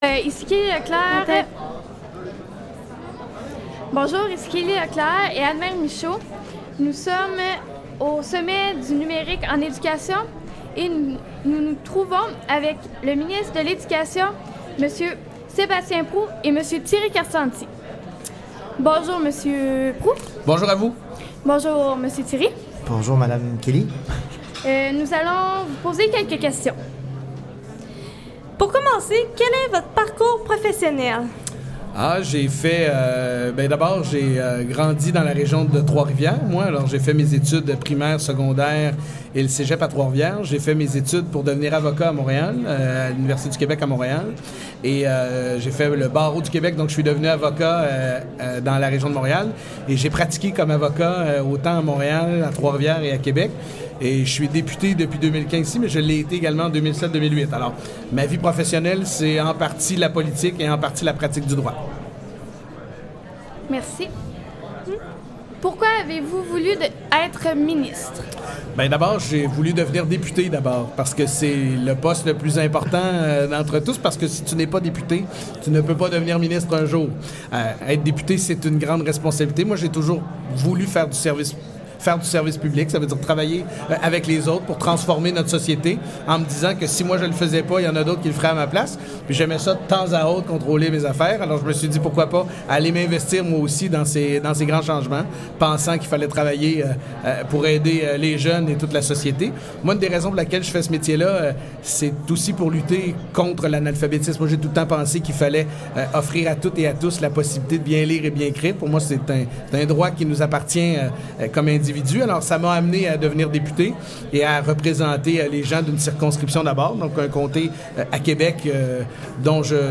Entrevue-avec-le-Ministre-de-lÉducation-Sébastien-Proulx-au-Sommet-du-Numérique.-4-mai-2018.mp3